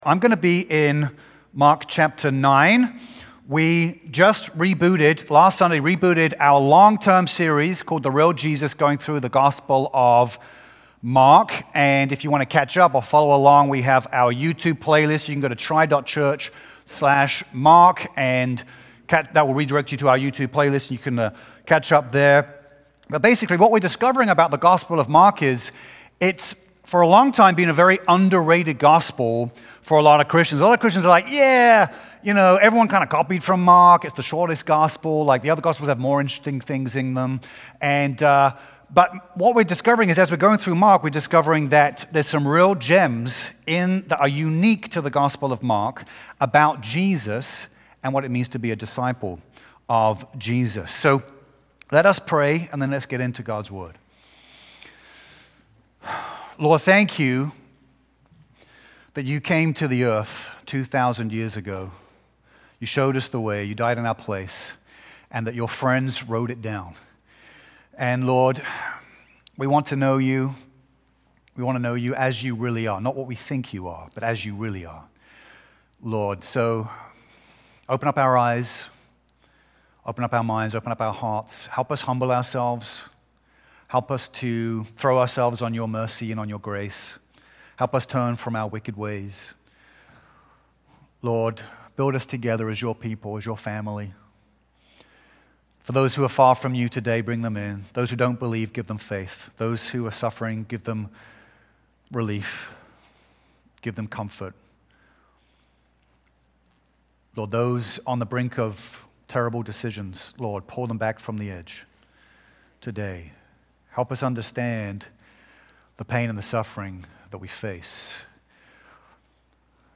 February-8-2026-Full-Sermon.mp3